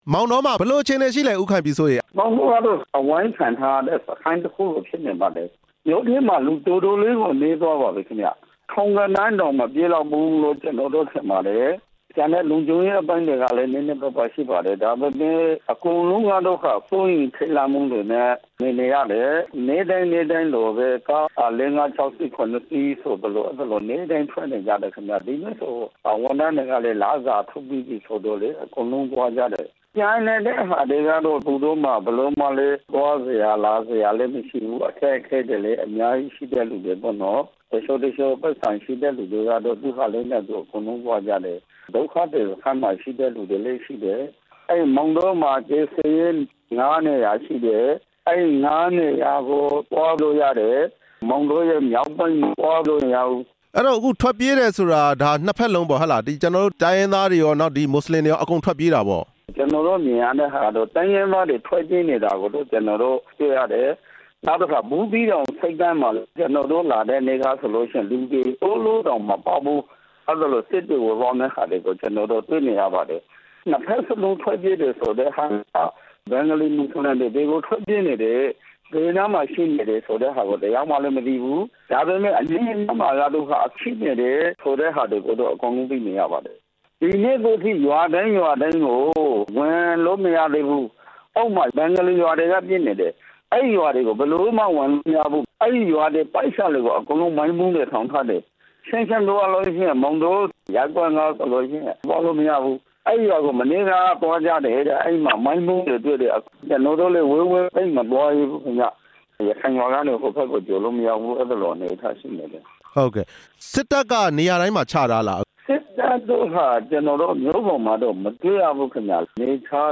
မောင်တော အခြေအနေ မေးမြန်းချက်